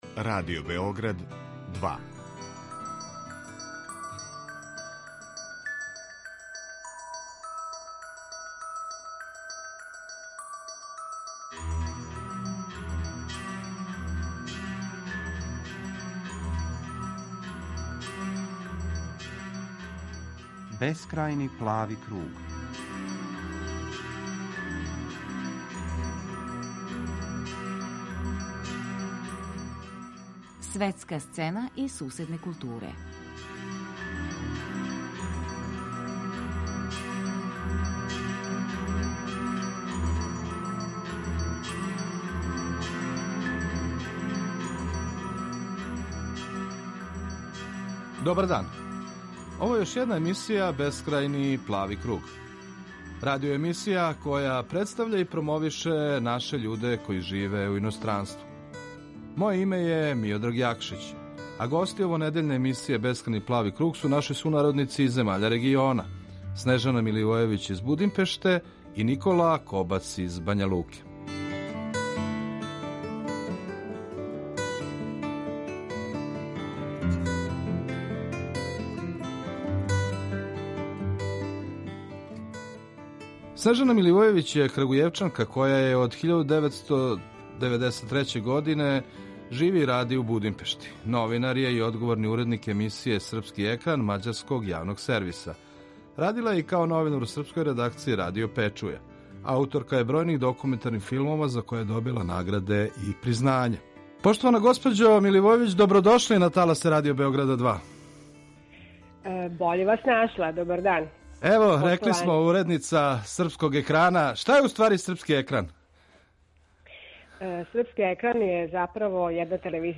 Гости овонедељне емисије Бескрајни плави круг су наши сународници из земаља региона